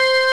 Organ01C.wav